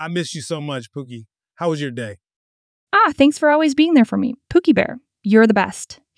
【読み方】
プーキー